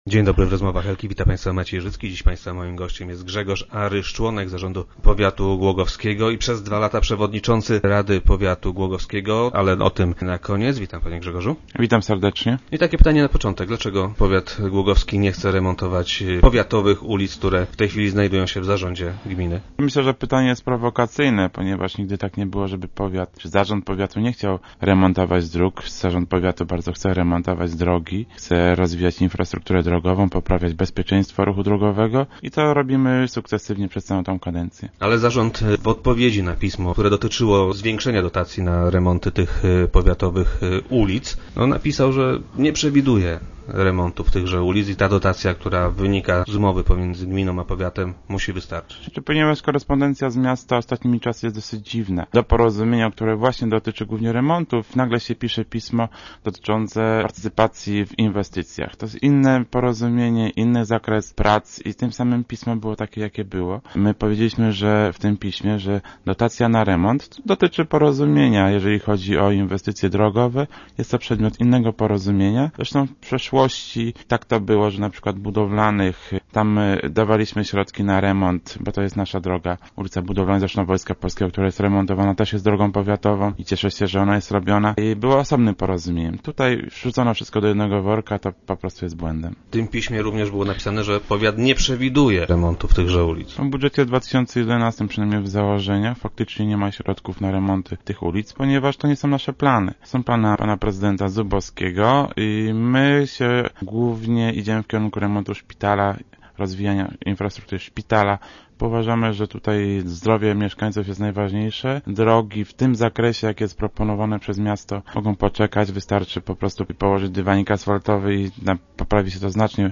Zarząd powiatu nie przewiduje zwiększenia dotacji dla miasta na ten cel. Gościem wtorkowych Rozmów Elki będzie Grzegorz Aryż, członek zarządu powiatu głogowskiego.